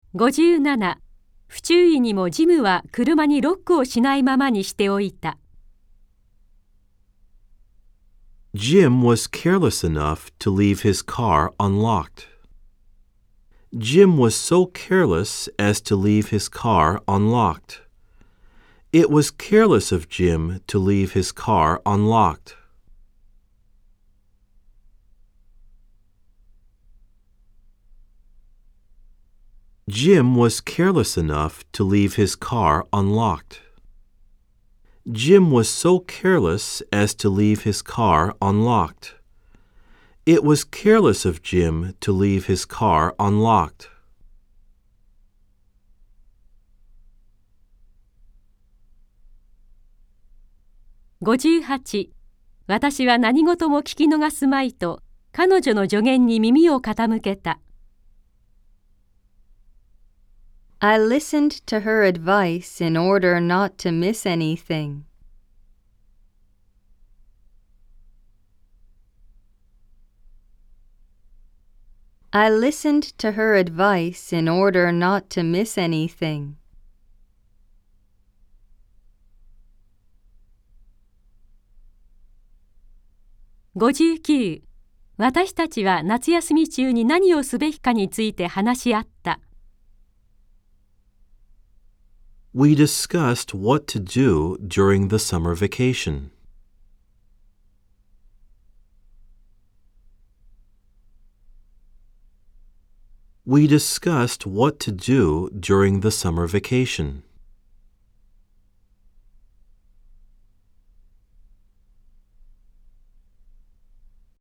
（4）暗唱例文100　各章別ファイル（日本文＋英文2回読み）
※（1）（2）では英文のあとに各5秒のポーズ、（3）（4）では各7秒のポーズが入っています。